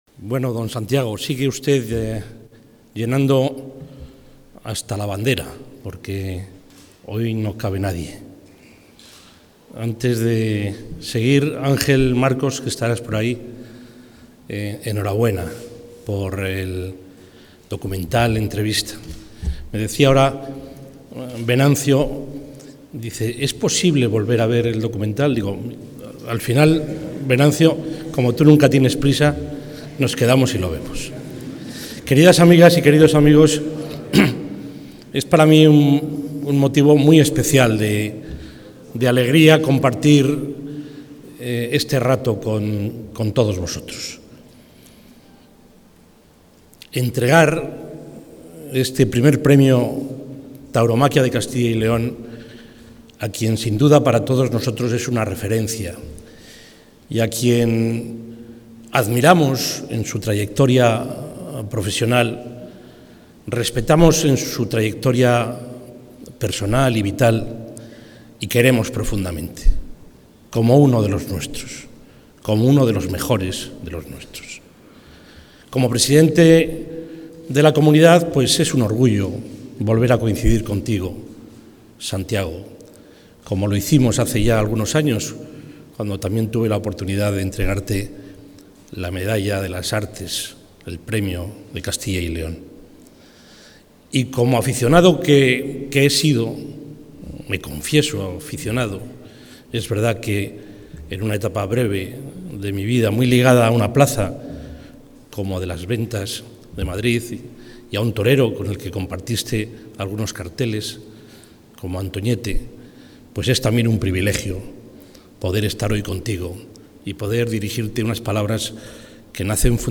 Audio presidente de la Junta.
El presidente de la Junta, Juan Vicente Herrera, ha entregado hoy el Premio de Tauromaquia de Castilla y León, en su primera edición, al torero Santiago Martín ‘El Viti’. El acto ha tenido lugar en el Monasterio Nuestra Señora del Prado de Valladolid.